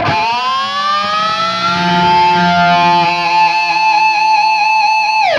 DIVEBOMB20-L.wav